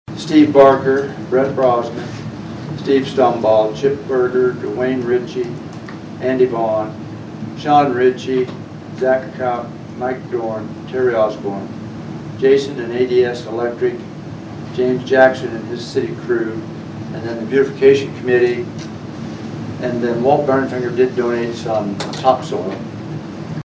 During Monday’s meeting, Alderman Andy Lester–who spearheaded the continued improvements at the Memorial–read off a list of individuals he wanted to thank for their help with the Memorial.